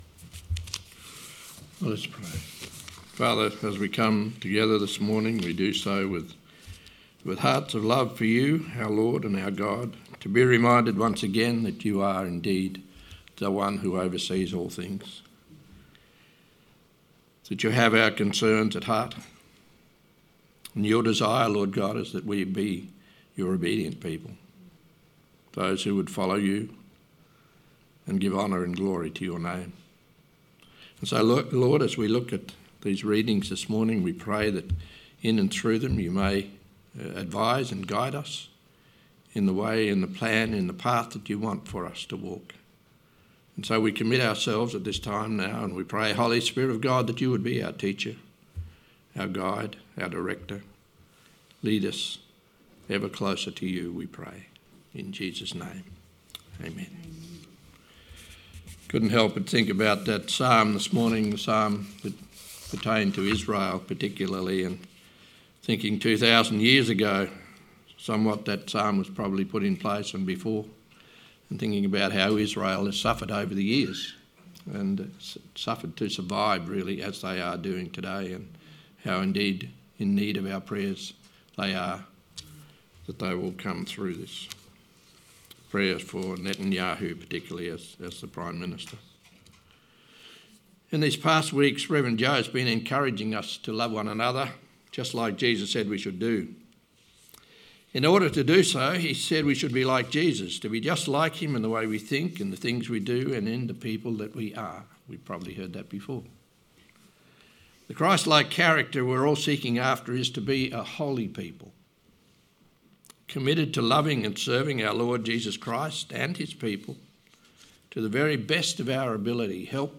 Sermon 29th September – A Lighthouse to the community